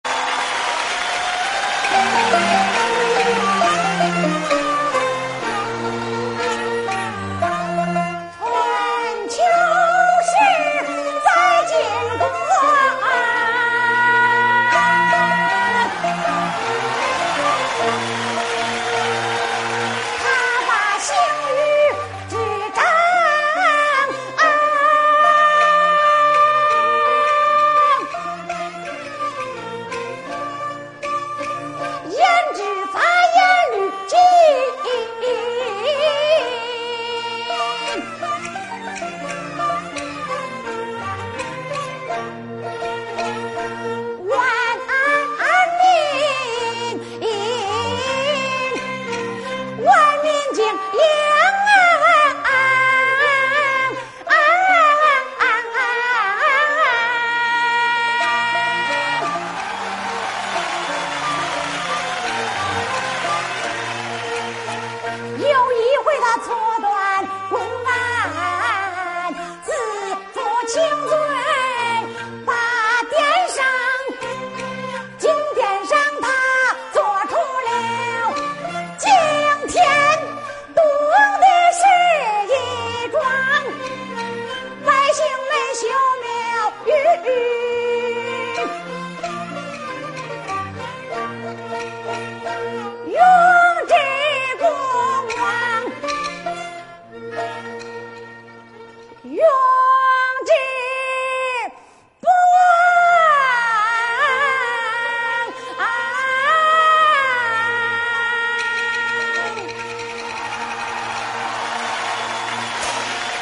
豫剧《血溅乌纱